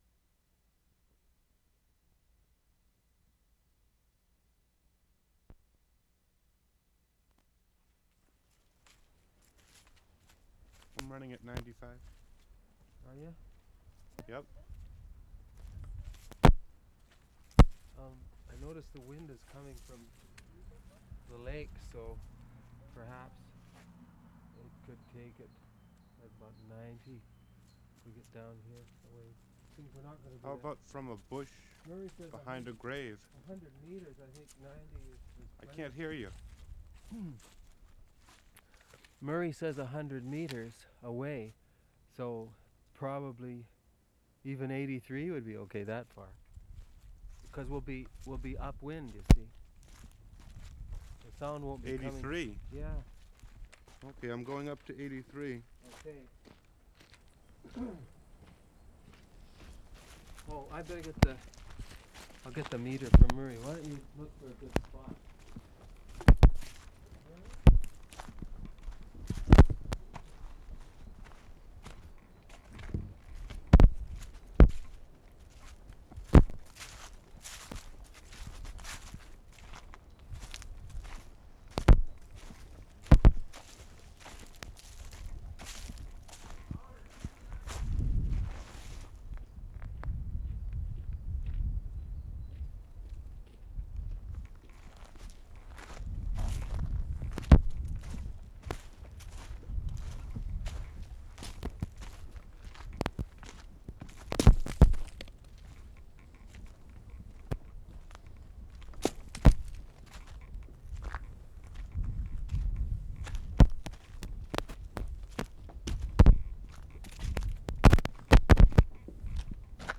WORLD SOUNDSCAPE PROJECT TAPE LIBRARY
LJUDER CHURCH, AMBIENCE and TALK
4. Whole take has to do with figuring out the intricacies of the Nagra: the recordist going crazy. A minute of ambience - very quiet!